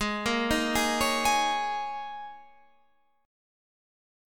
Absus2sus4 chord